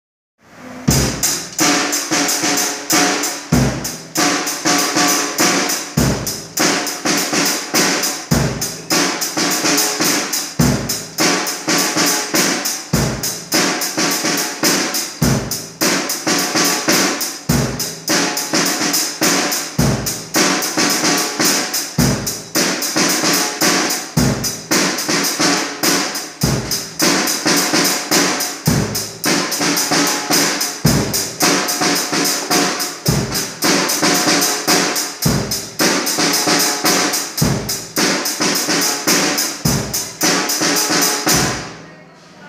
na batera